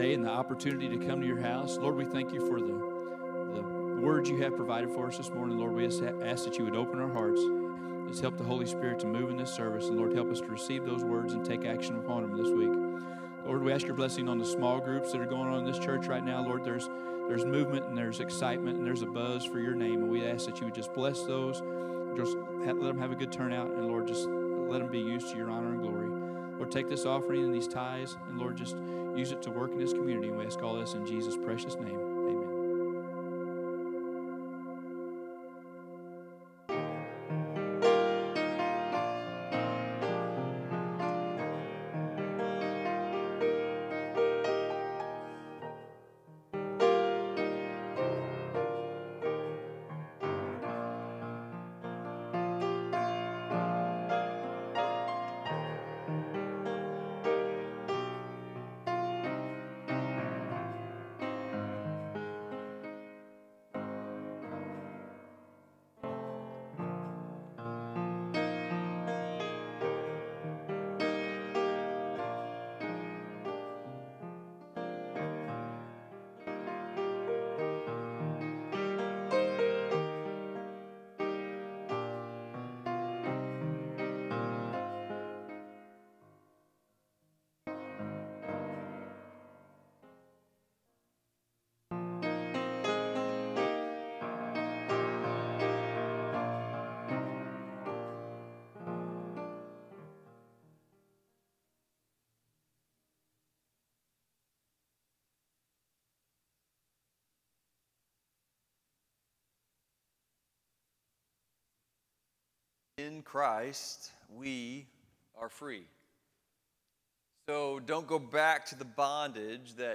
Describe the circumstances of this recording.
FBC Potosi - Sunday Service